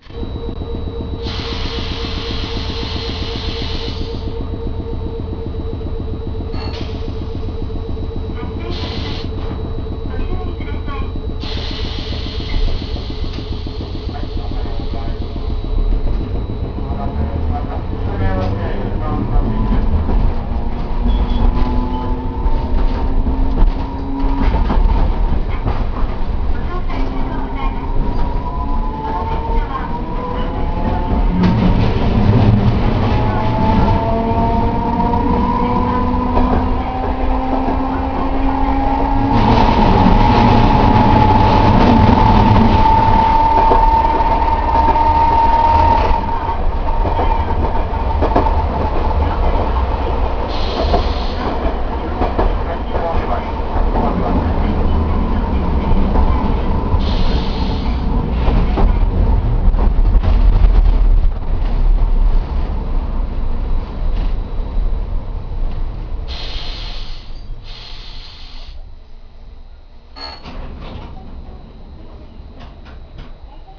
・200形走行音
【桟橋線】高知駅→高知橋（1分13秒：402KB）
見た目にもわかる通りとても年季の入った車両なので、吊り掛けの音も重々しく、コンプレッサーの音もいかにも古い車両といった趣。趣味的にはとても良いものの、自動放送は全然聞こえませんし、揺れもかなりのレベル。